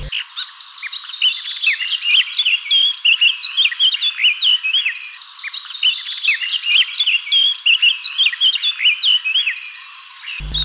الصفاري وله اكثر من مقطع صوتي
blackcap_w.wav